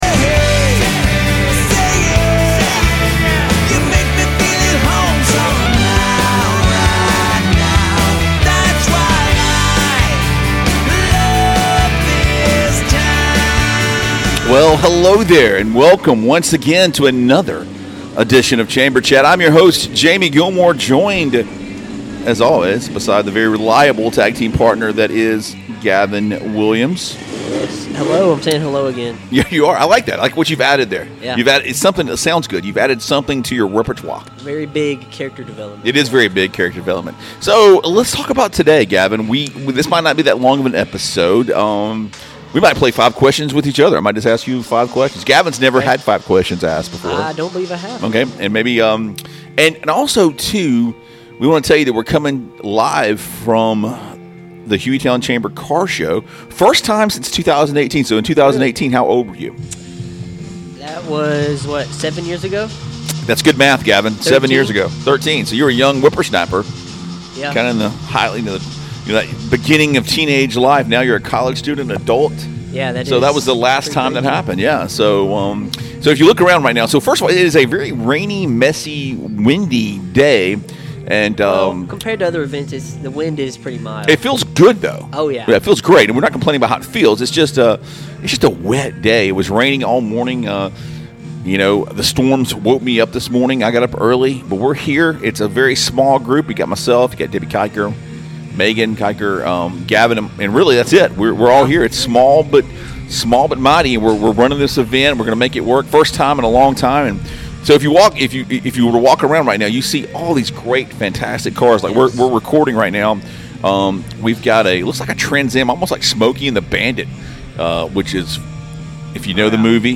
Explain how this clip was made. On your mark get set GO! we are at the car show. buckle up as today is all about cars.